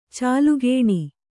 ♪ cālugēṇi